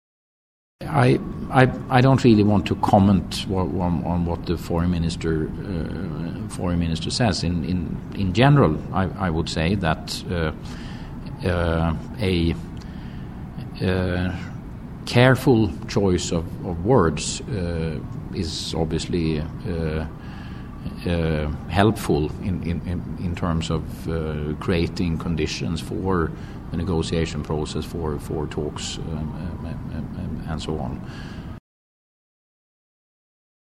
Հատված Փիթեր Սեմնեբիի հետ հարցազրույցից